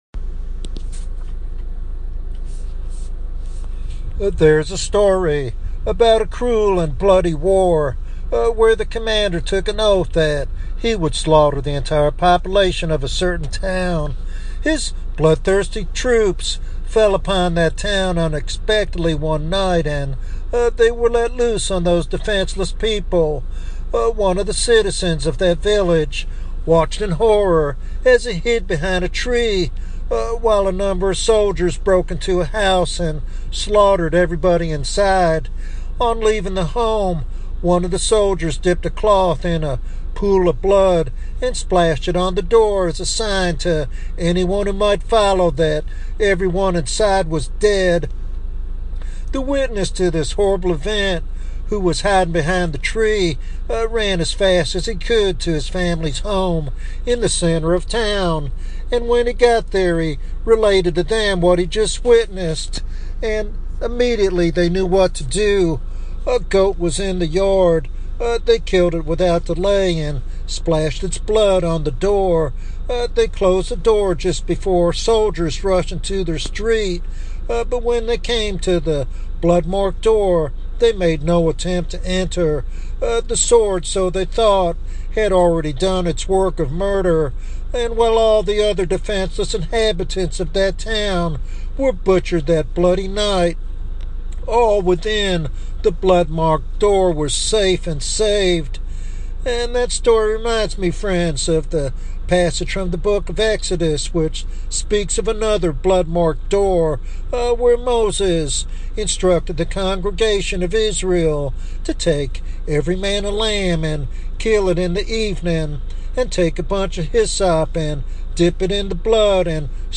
This sermon is a compelling reminder of the necessity of being covered by Christ's sacrifice for eternal life.